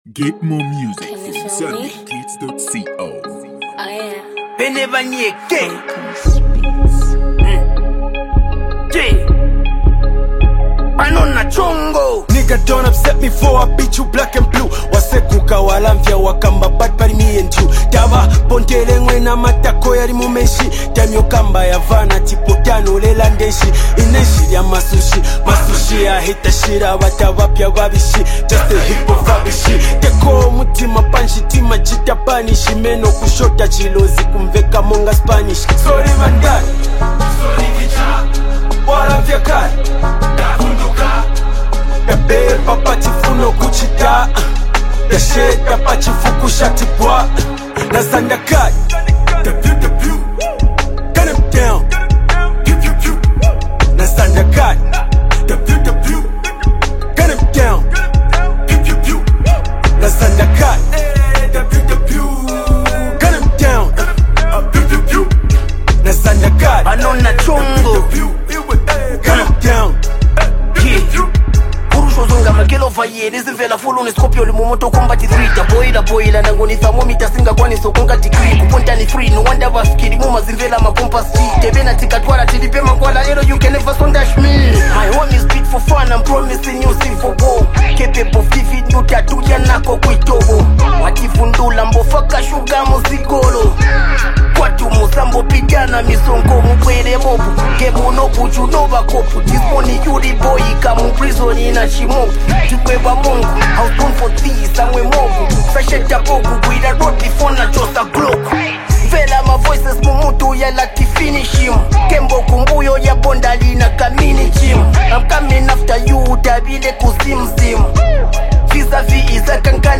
one of Zambia’s most celebrated rap artists
sharp lyrical delivery